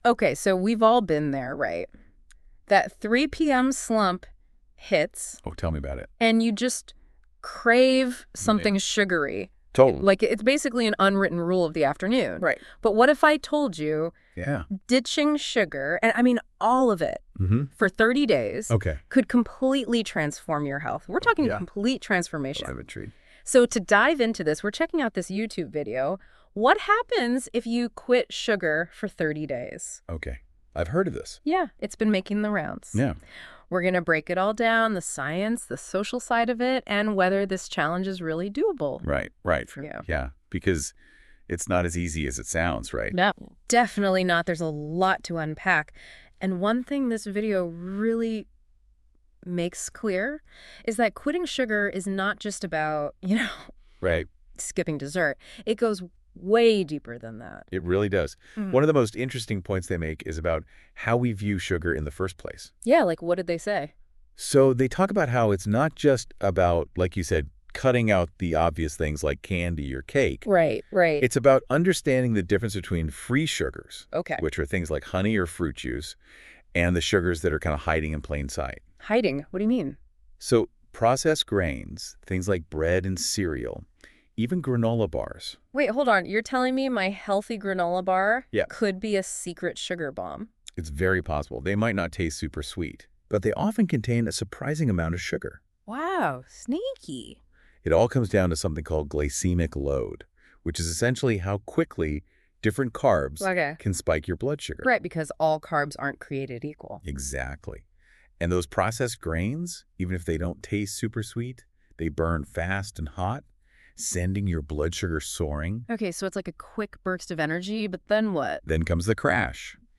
Disclaimer: While the voices you hear in The Future Thought Exchange are powered by advanced AI, they can sometimes mispronounce words, misunderstand concepts, or provide information that may not be entirely accurate.